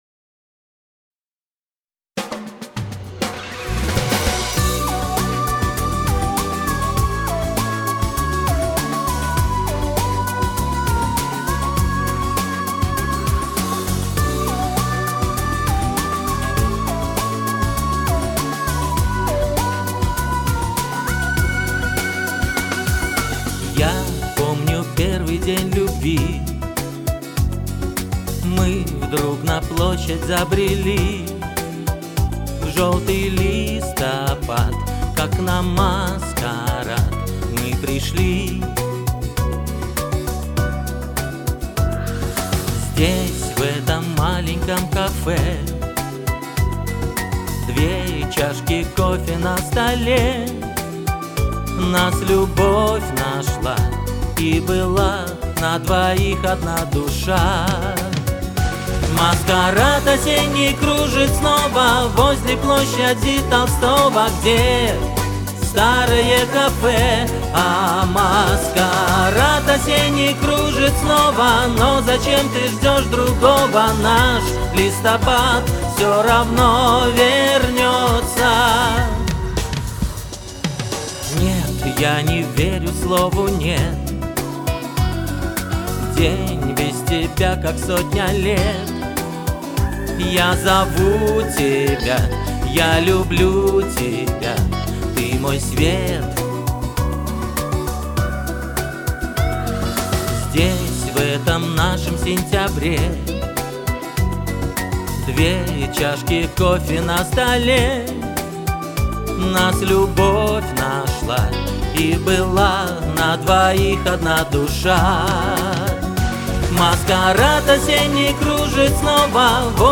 Главное, что исполнение чистенькое, без "лажи" у обоих. 3:3